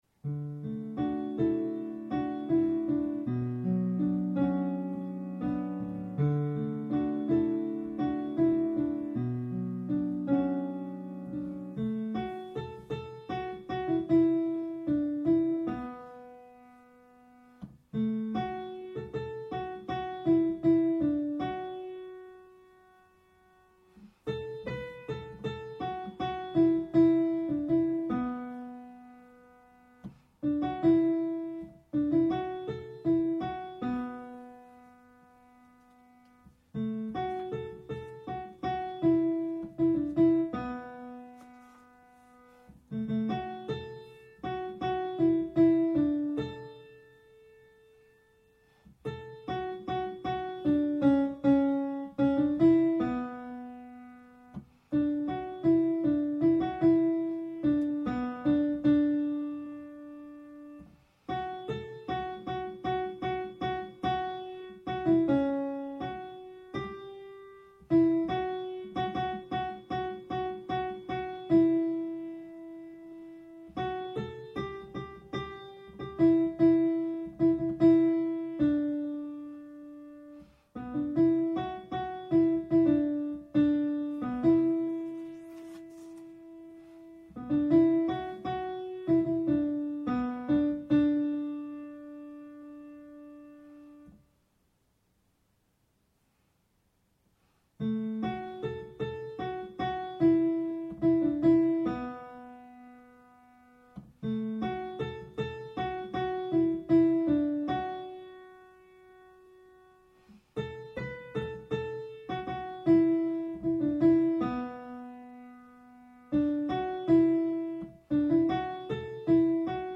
For four parts
Alto